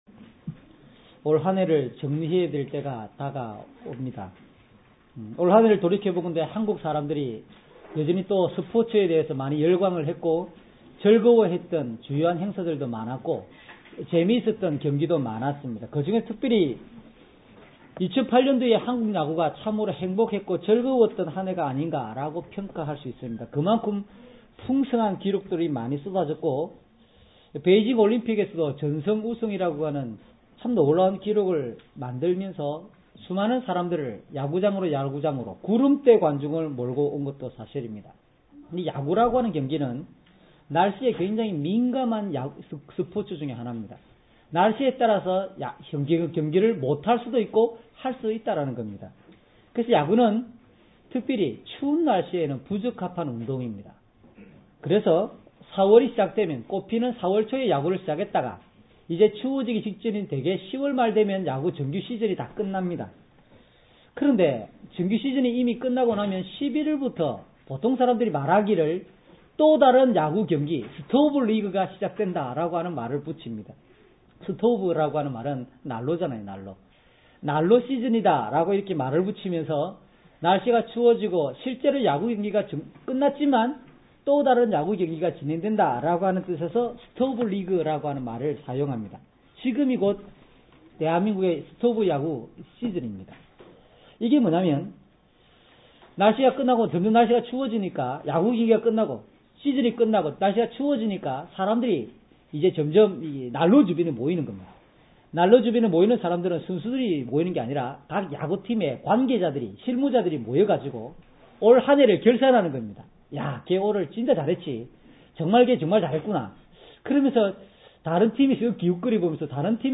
주일설교 - 08년 11월 30일 "말씀은 새로운 삶의 기준입니다."